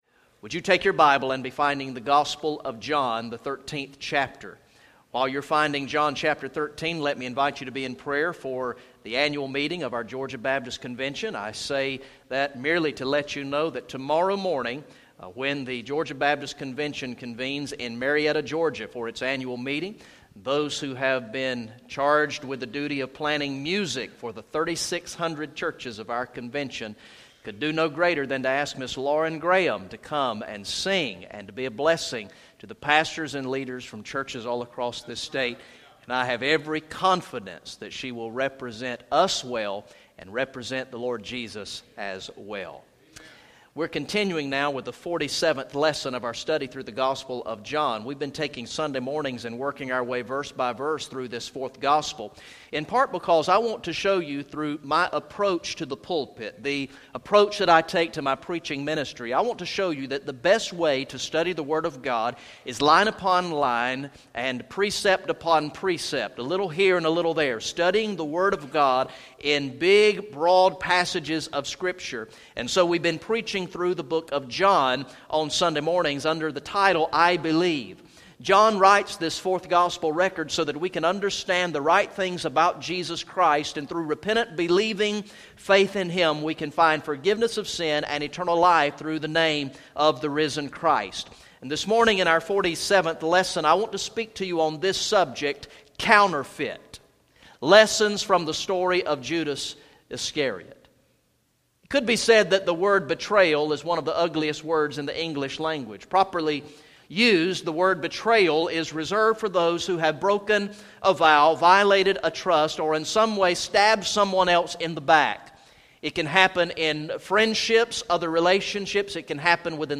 Message #47 from the sermon series through the gospel of John entitled "I Believe" Recorded in the morning worship service on Sunday, November 8, 2015